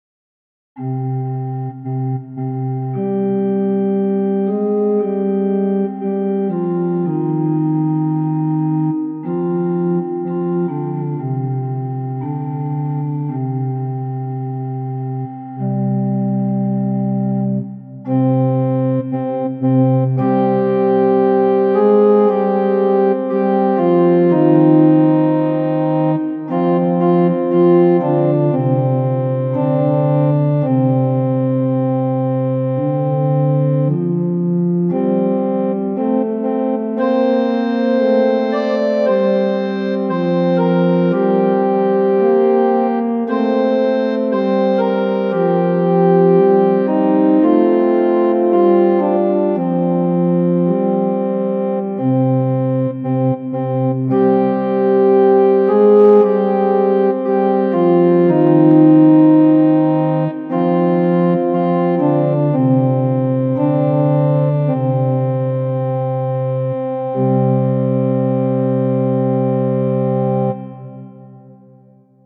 Tonality = c Pitch = 440 Temperament = Equal